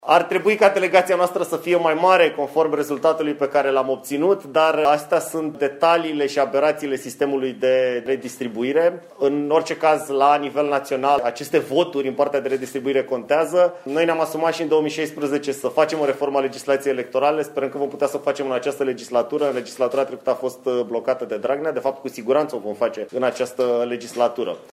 Se impune o modificare a legislației electorale, consideră deputatul USR, Cătălin Drulă, care susține că va propune acest lucru.